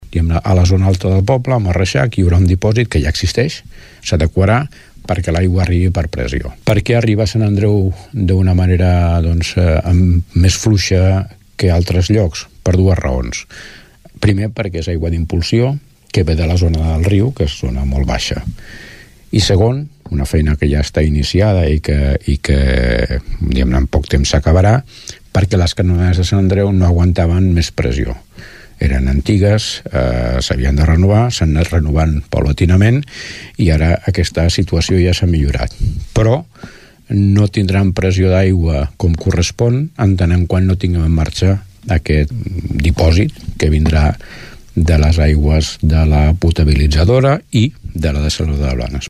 El batlle torderenc, Joan Carles Garcia, explica les millores que comportarà aquest canvi.